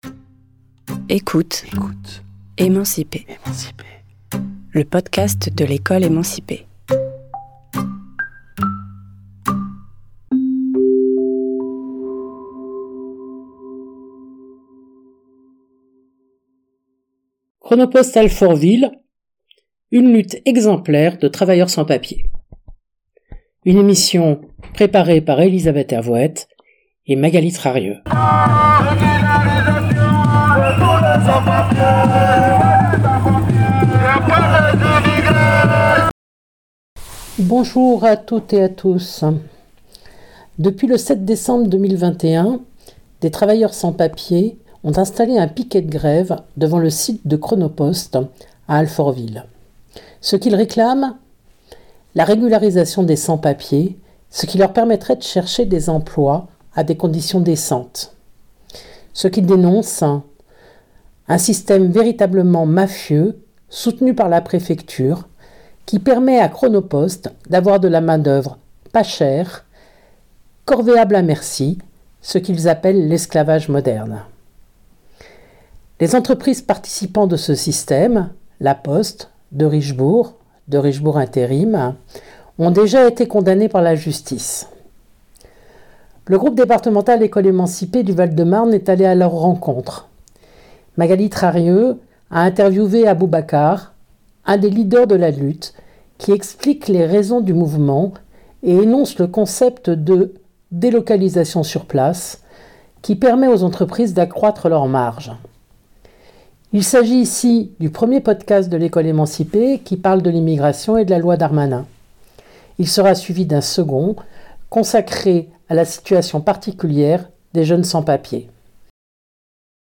Le groupe départemental de l'Ecole Emancipée du 94 est allé à leur manifestation du 18 avril et a interviewé un de leurs représentants.